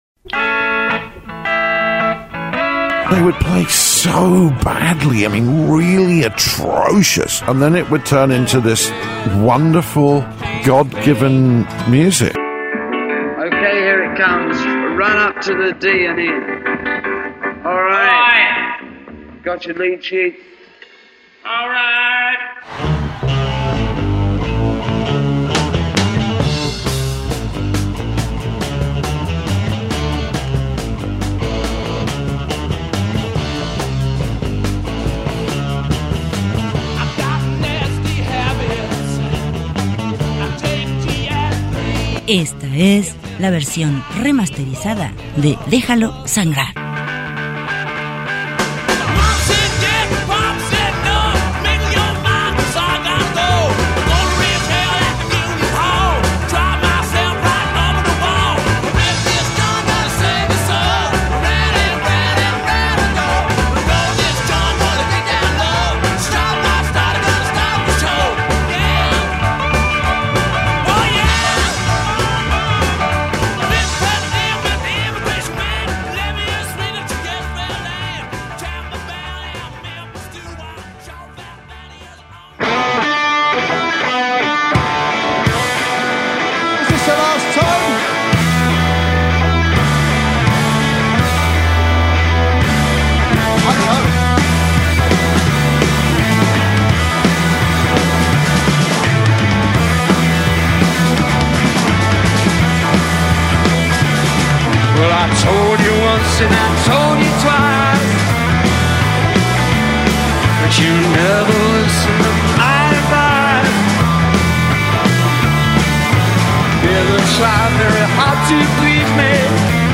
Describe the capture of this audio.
Por tener que atender asuntos personales, el programa de este día es grabado.